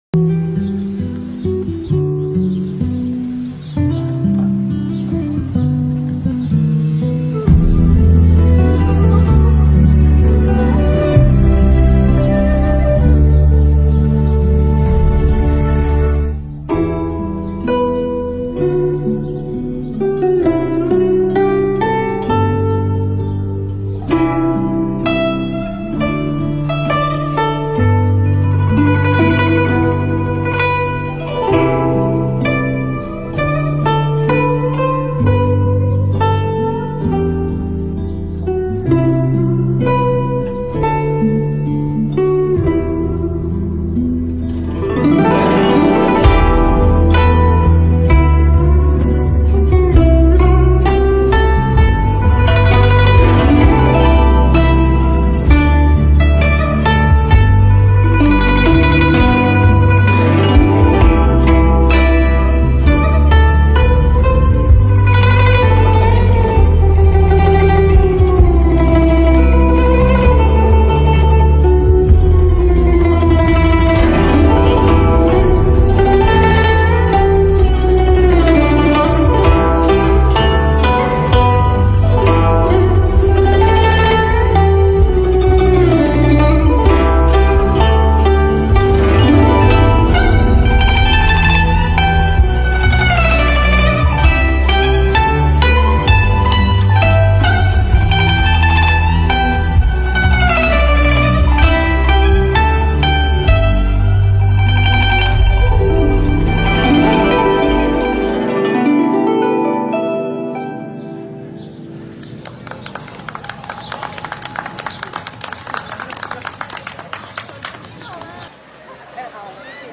âm thanh trong trẻo và sâu lắng.
tiếng đàn tranh